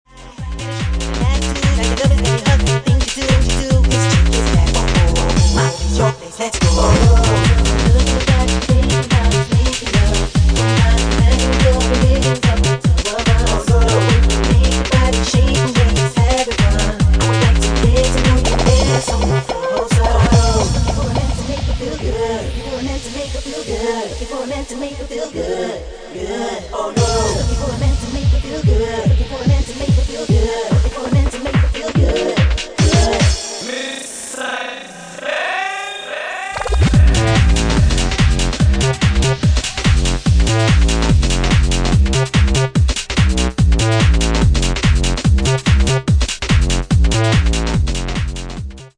Format:12" Vinyl
Genre:Bassline House